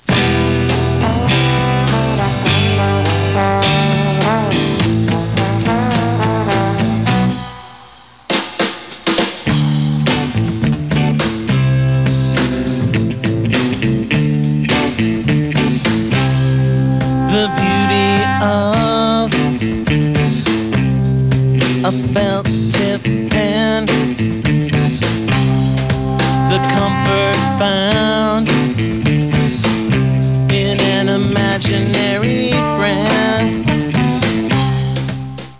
Live
Mono